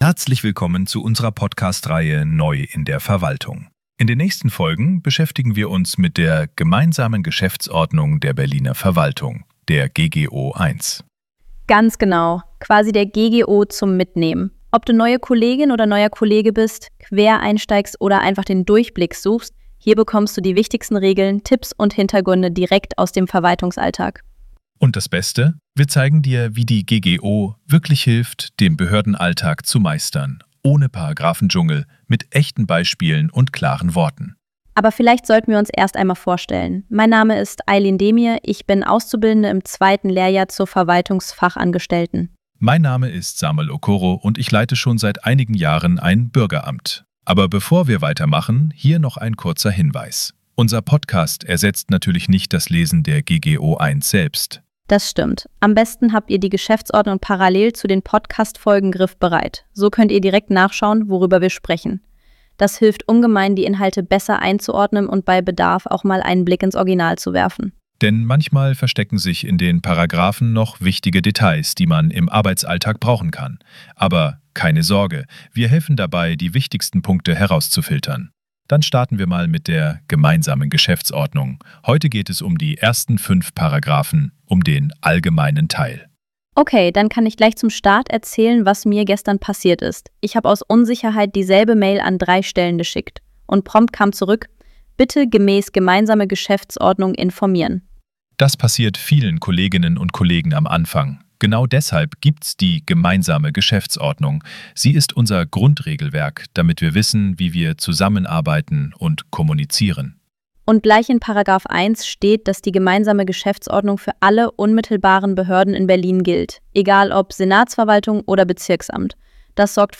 Die Erstellung dieser Podcastreihe erfolgte mit Unterstützung von Künstlicher Intelligenz.